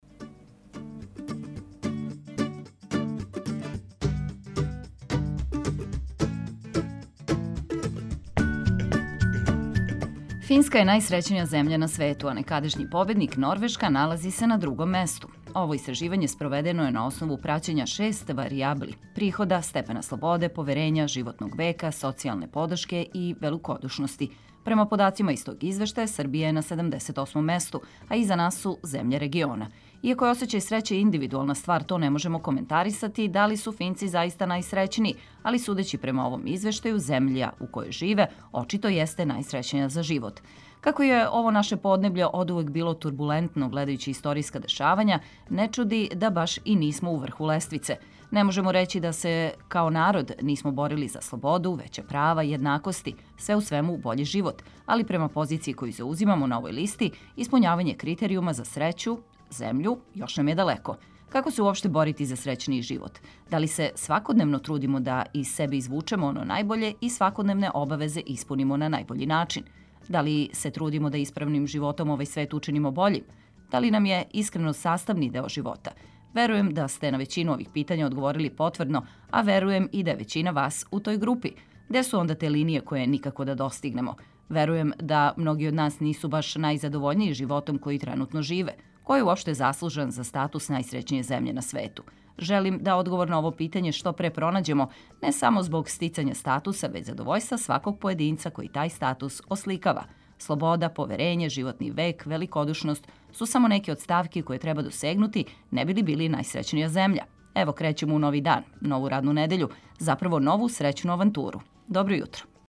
Пренећемо све важне информације и у вашем друштву слушати сјајну музику за расањивање.